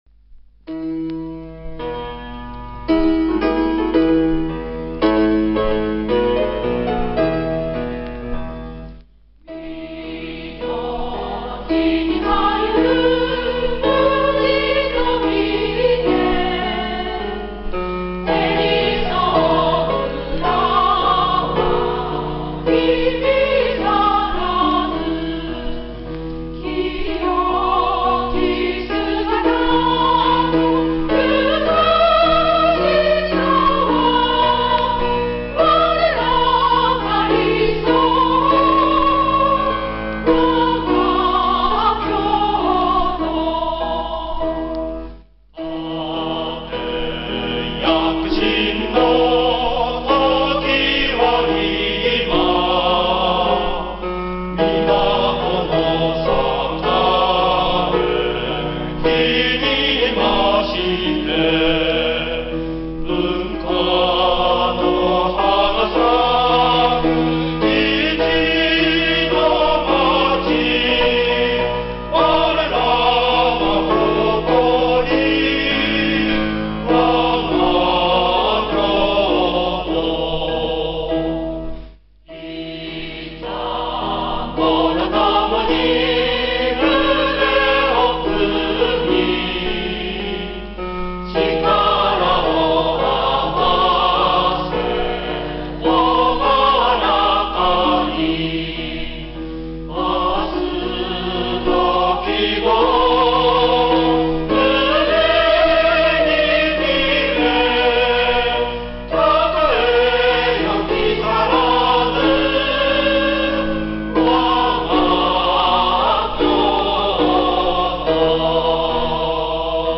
木更津市民歌（合唱） （mp3） (音声ファイル: 4.8MB)